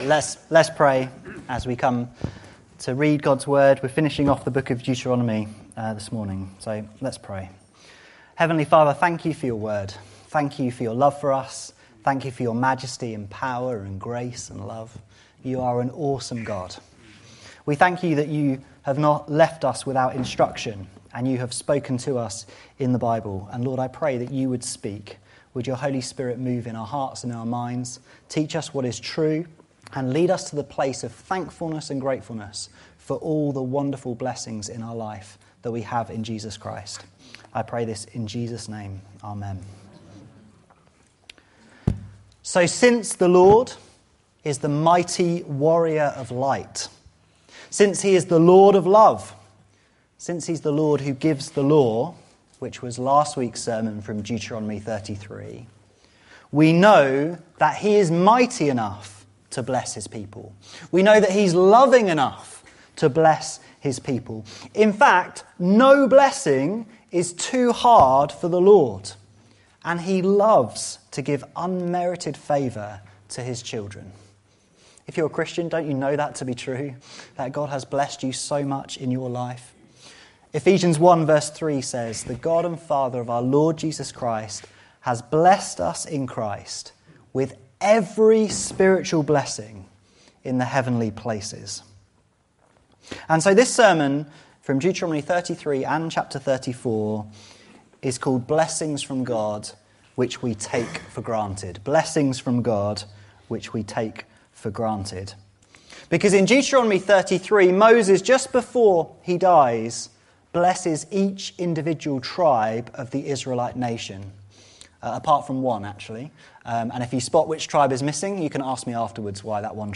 This sermon reflects upon the many glorious blessings that God lavishes upon his people that we often take for granted.